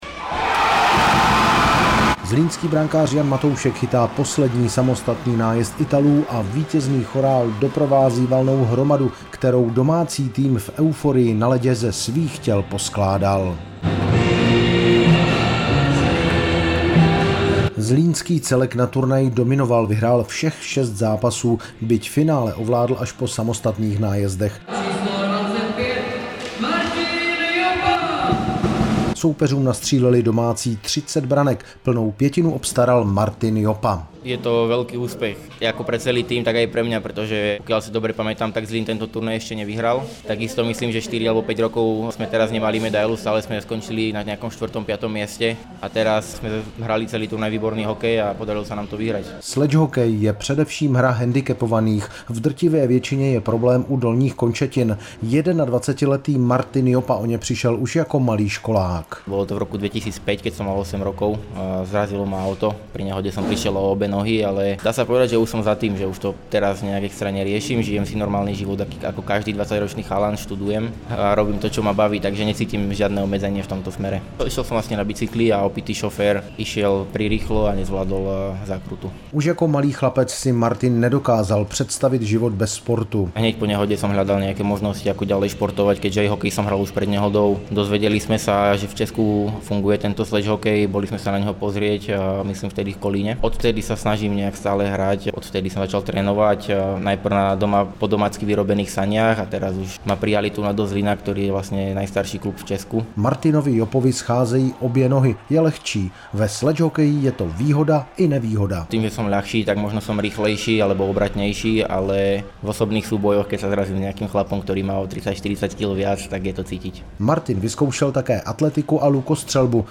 Reportáž po vítězném finále LAPP CUP ZLÍN 2018